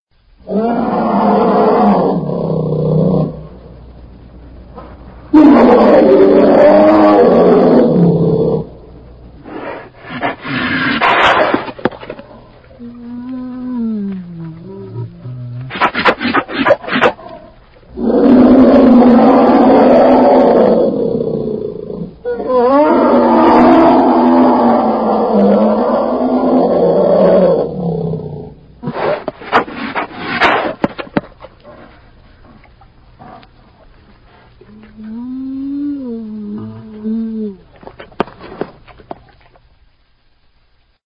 دانلود صدای خرس از ساعد نیوز با لینک مستقیم و کیفیت بالا
جلوه های صوتی